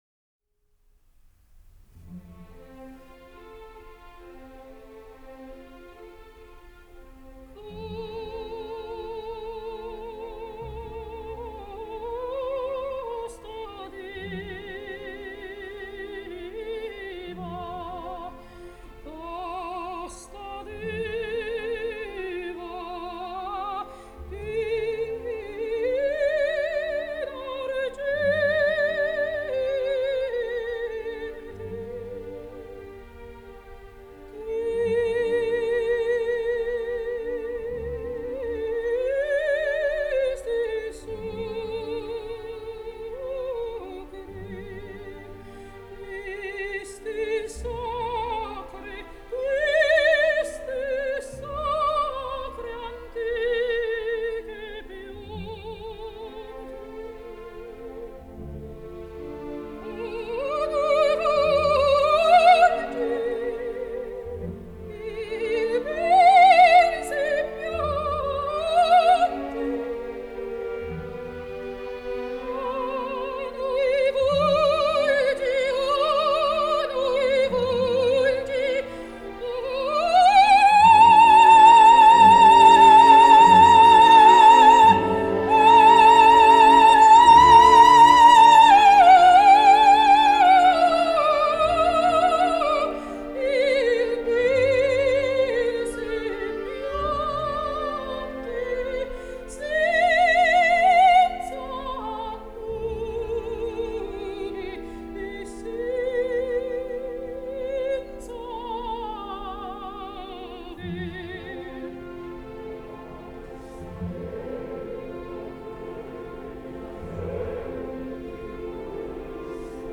La conférence a trouvé son point d’orgue et a ému profondément l’auditoire avec l’écoute d’un extrait de la cavatine intitulée « Casta Diva » tirée de l’opéra « Norma ».